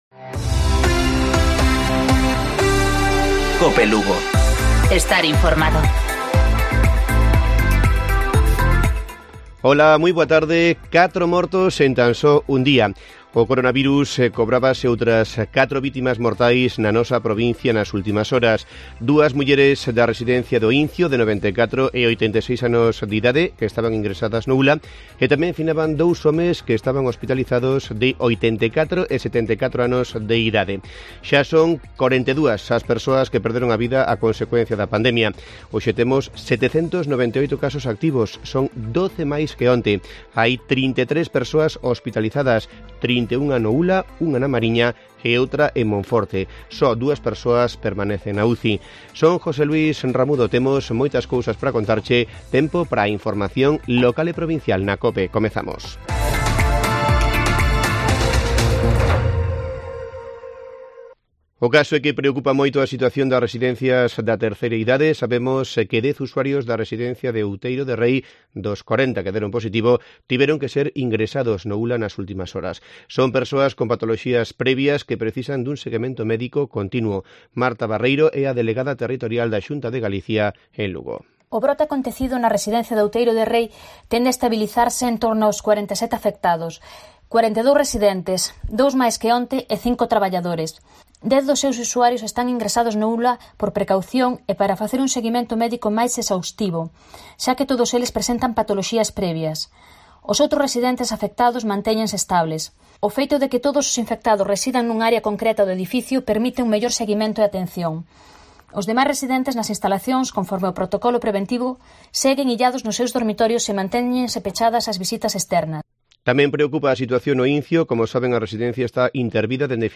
Informativo Provincial de Cope Lugo. 03 de septiembre. 13:20 horas